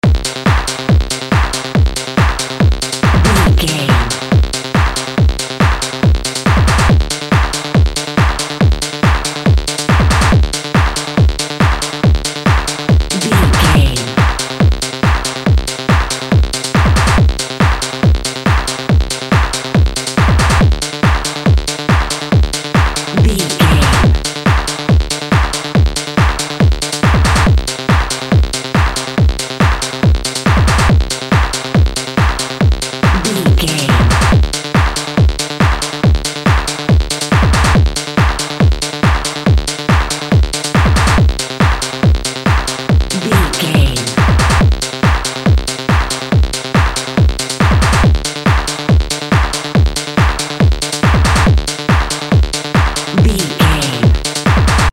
Atonal
Fast
energetic
futuristic
hypnotic
frantic
drum machine
techno
synth lead
synth bass
Electronic drums
Synth pads